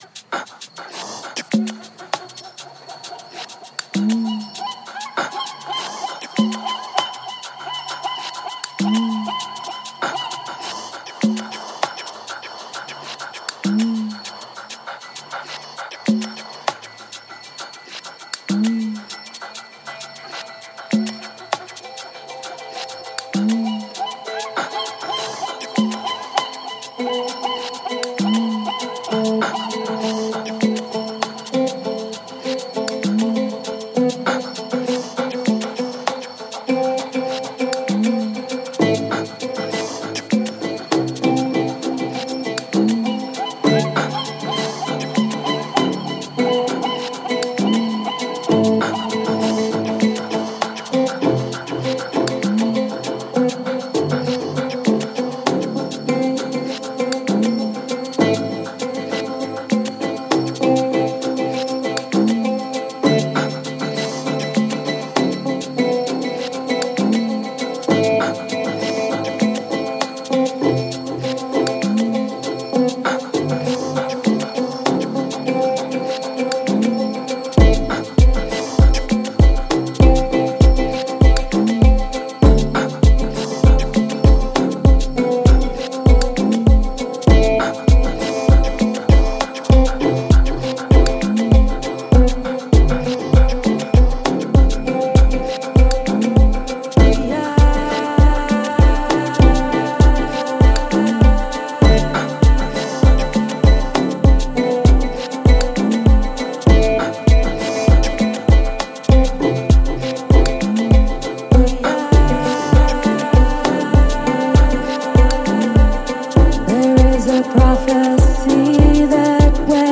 downtempo